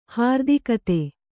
♪ hārdikate